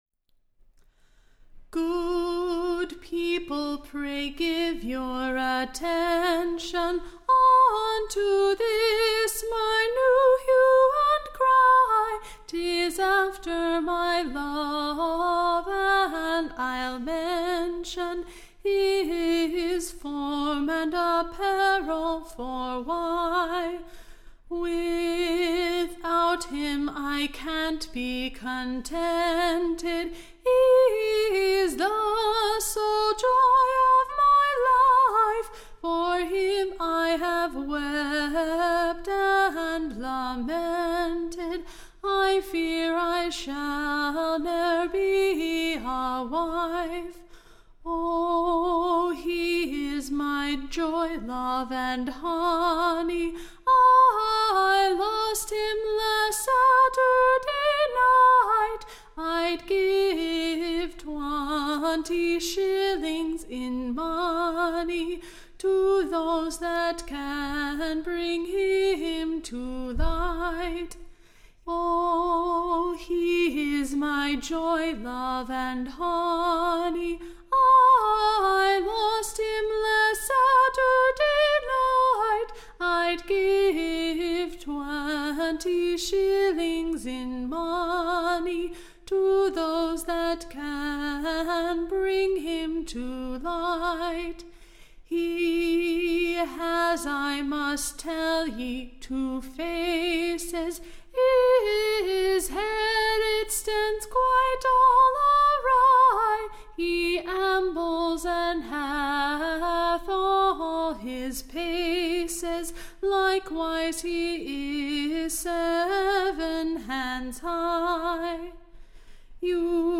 Recording Information Ballad Title The London lasses Hue=and=Cry / After Her / Dearly Beloved Robin, / Whom she unluckily lost last Saturday Night.